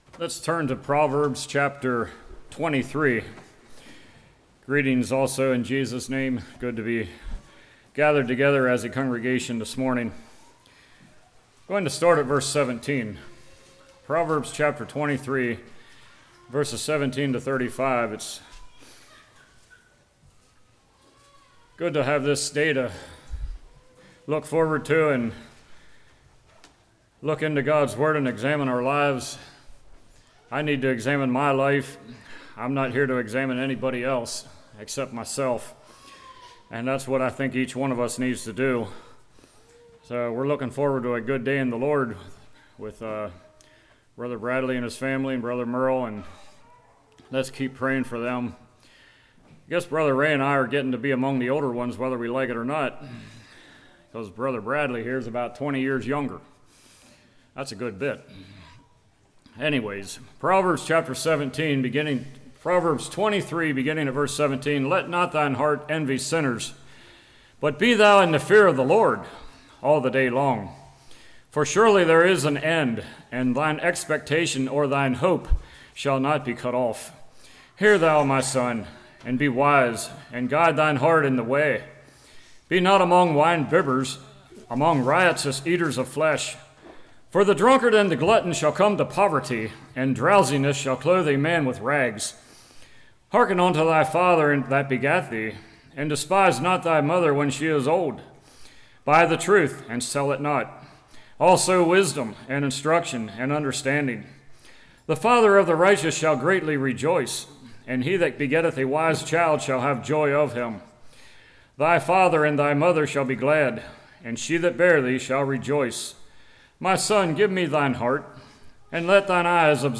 Proverbs 23:17-35 Service Type: Morning Food Pleasure Prayer « Self Examination Oh